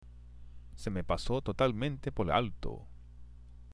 （セ　メ　パソ　トタルメンテ　ポル　アルト）